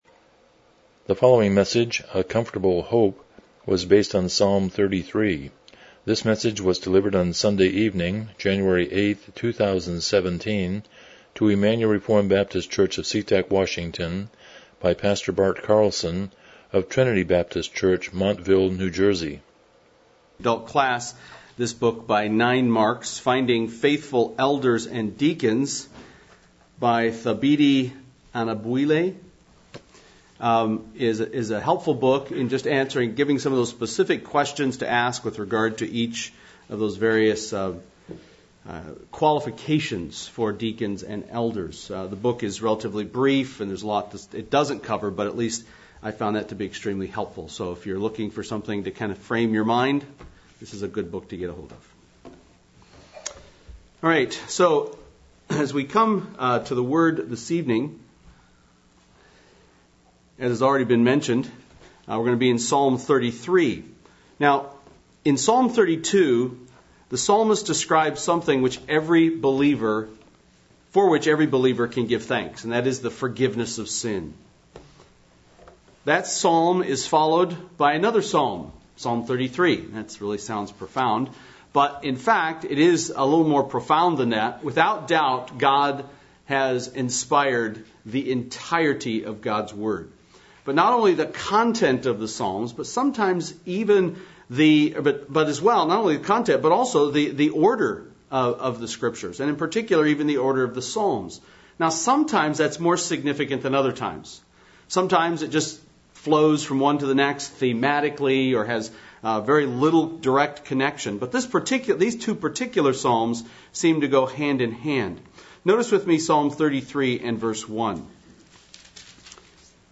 Miscellaneous Service Type: Evening Worship « Celebrating the Blessedness of Forgiveness